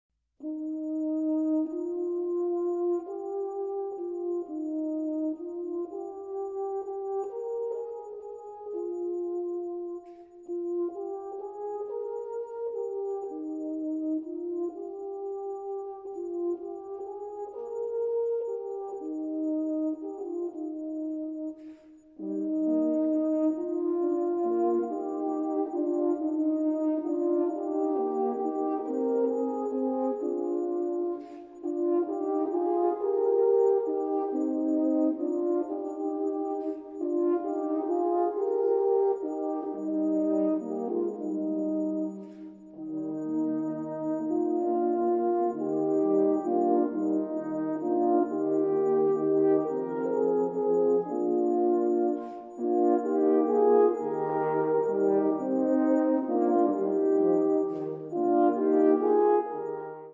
Nahráno digitálně 1988 na zámku Dobříš
(64 kb/sec. stereo)
- canon 3 481 kb 1:00 min